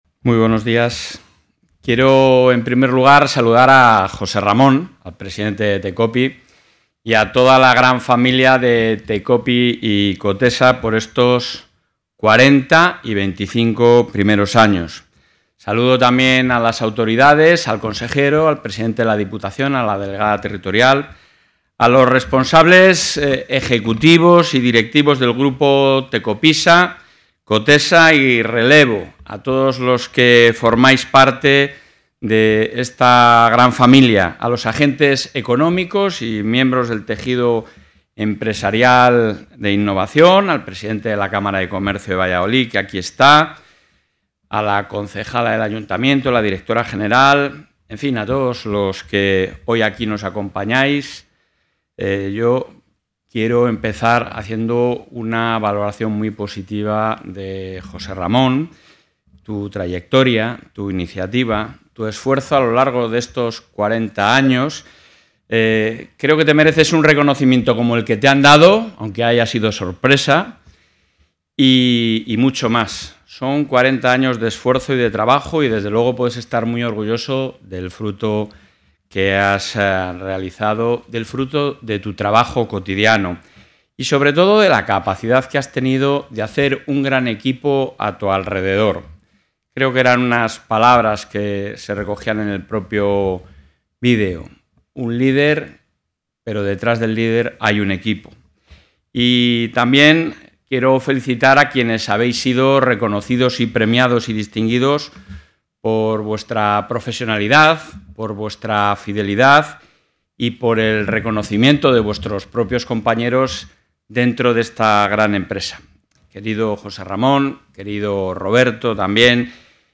Intervención del presidente.
El presidente de la Junta de Castilla y León, Alfonso Fernández Mañueco, ha participado hoy en Valladolid en el acto conmemorativo del 40 aniversario de Tecopysa y el 25 aniversario de COTESA, del Grupo Tecopy, donde ha subrayado el compromiso del Ejecutivo autonómico con la innovación y la internacionalización de las empresas de la Comunidad, a las que continuará apoyando para crecer y generar riqueza y empleo.